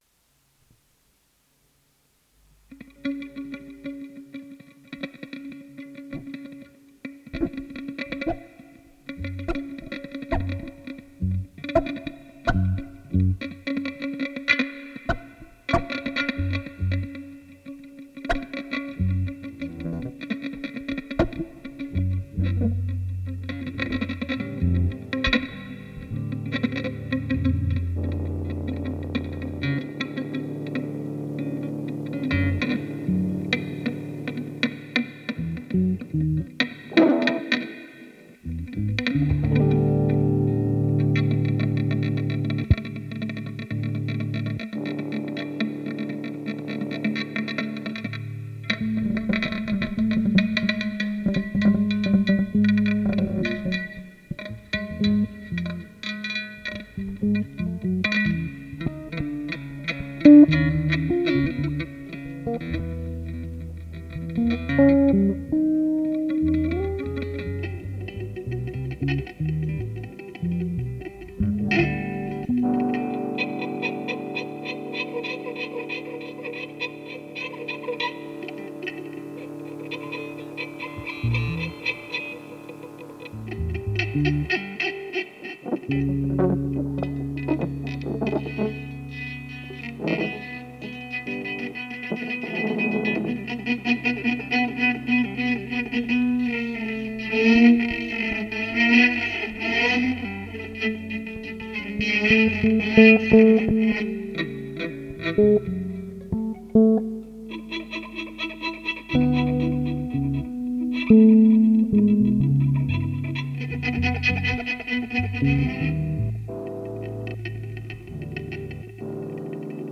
Wie könnt‘ es anders sein: Gitarre ;)